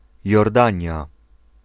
Ääntäminen
Ääntäminen US RP : IPA : /ˈdʒɔːdən/ US : IPA : /ˈdʒɔːrdən/ Lyhenteet ja supistumat (laki) Jor.